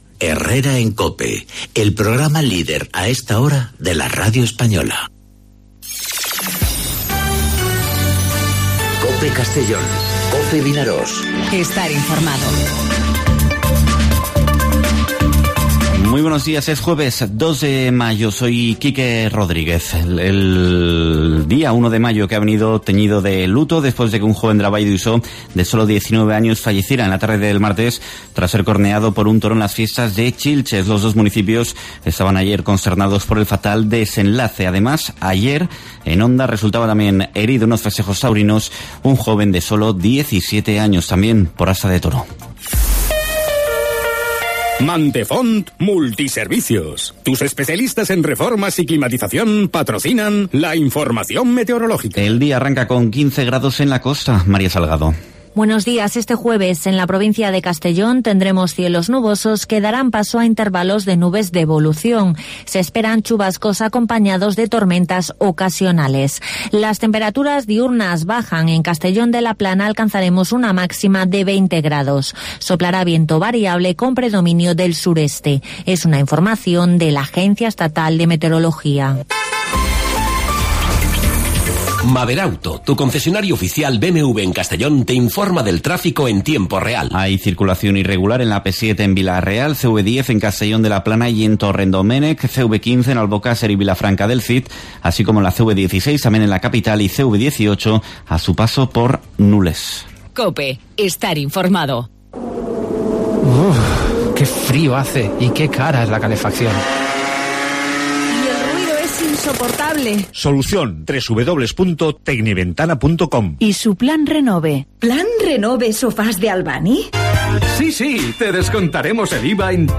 Informativo 'Herrera en COPE' Castellón (02/05/2019)